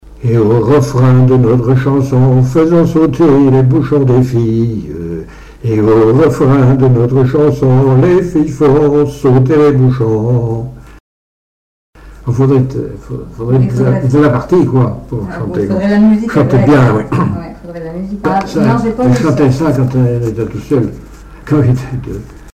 Genre brève
Pièce musicale inédite